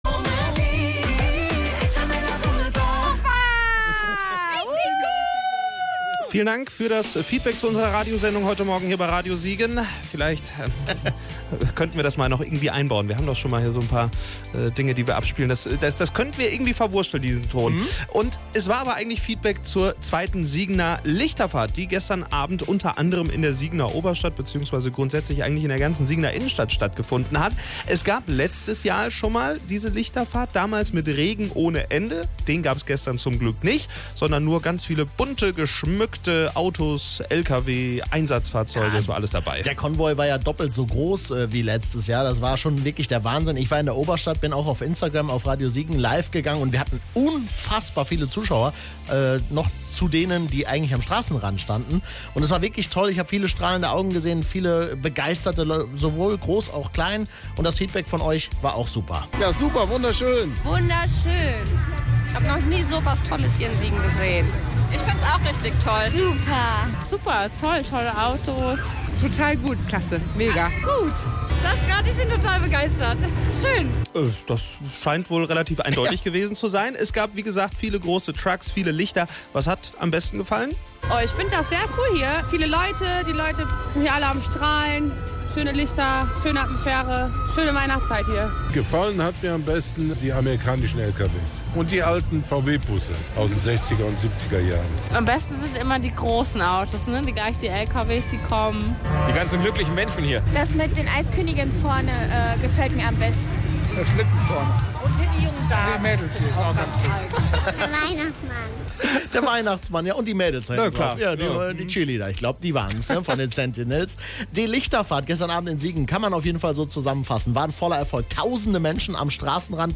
Radio Siegen war mit einem Livestream und mit einem Mikro dabei.